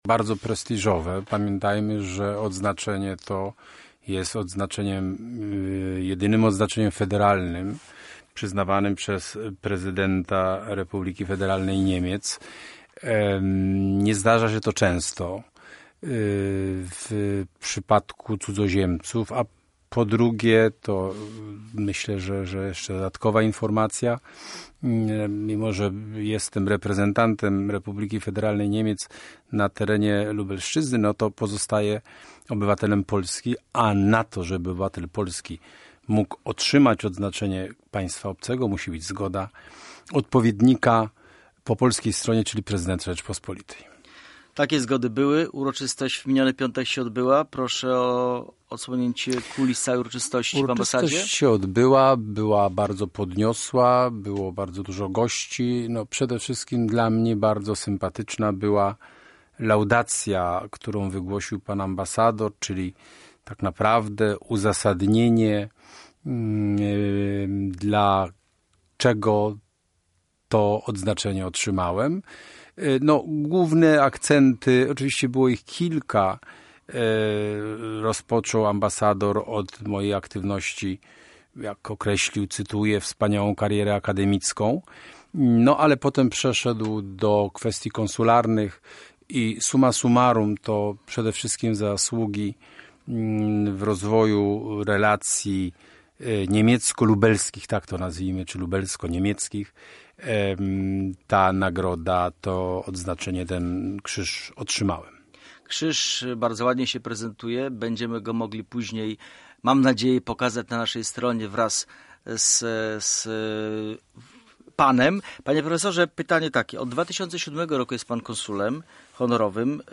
Podczas rozmowy w studiu Radia Centrum przyznał, że to wyróżnienie jest dla niego bardzo ważne.